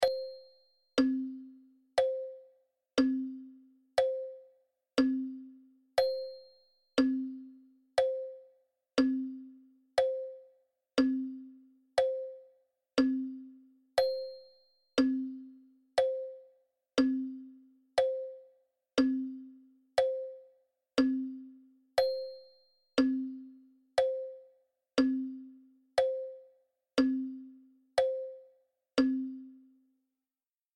دانلود صدای ساعت 8 از ساعد نیوز با لینک مستقیم و کیفیت بالا
جلوه های صوتی